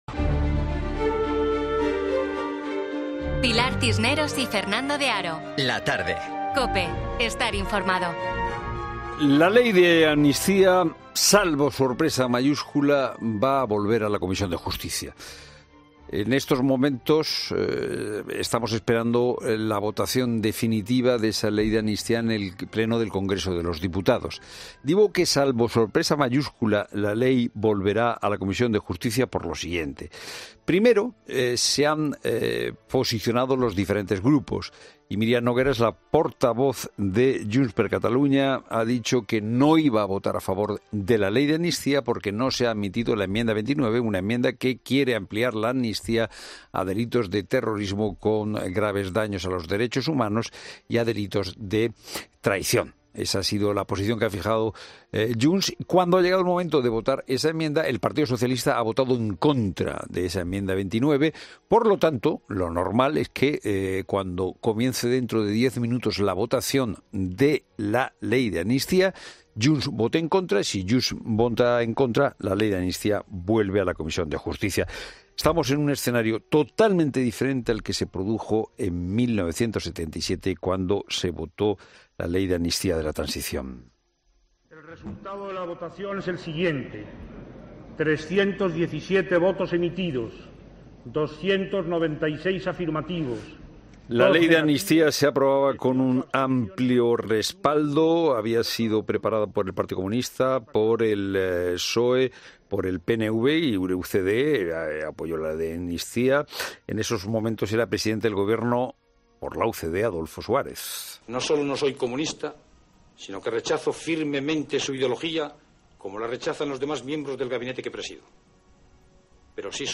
Rafael Arias Salgado, ex ministro de Presidencia con Adolfo Suárez, analiza esta nueva norma en 'La Tarde de COPE'